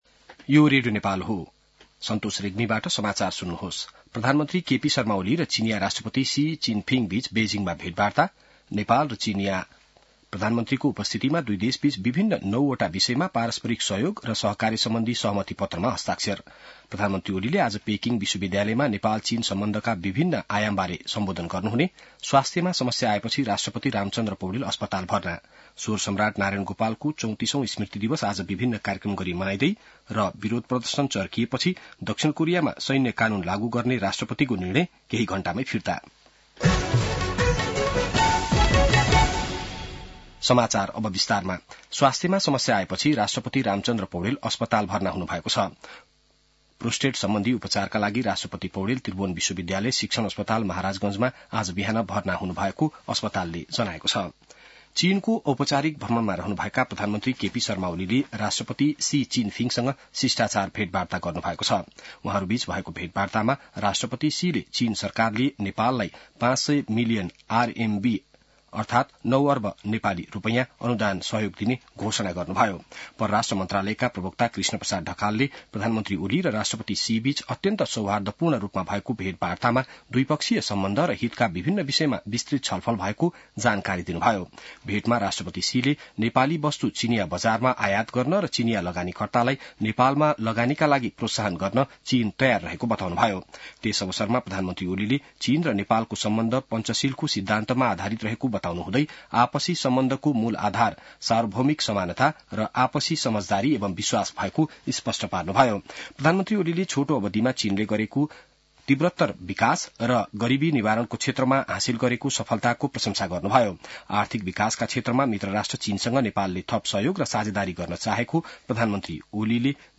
बिहान ९ बजेको नेपाली समाचार : २० मंसिर , २०८१